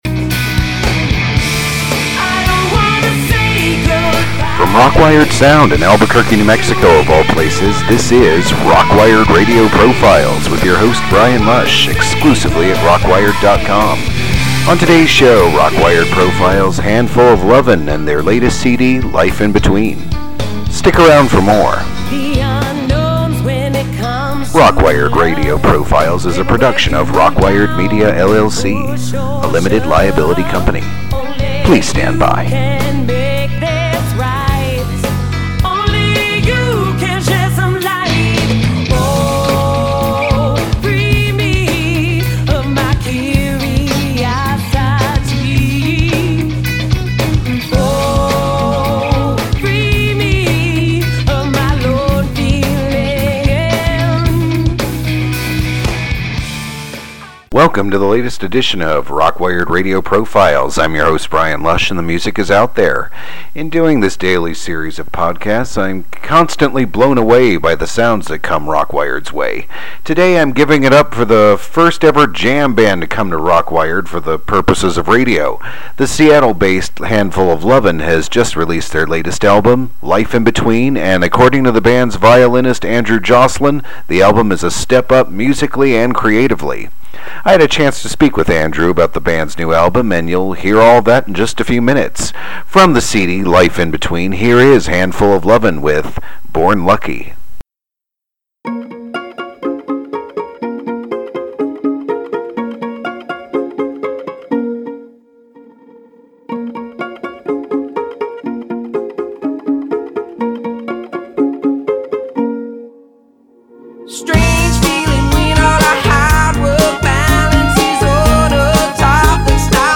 ROCKWiRED RADiO iS AN ONLiNE PODCAST FEATURiNG NEW MUSiC AND EXCLUSiVE iNTERViEWS AND iS A PRODUCTiON OF ROCKWiRED MEDiA LLC.